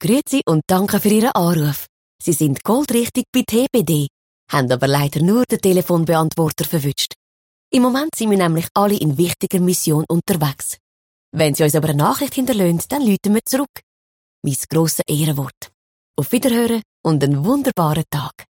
Telefonansage Schweizerdeutsch (ZH)
Schauspielerin mit breitem Einsatzspektrum.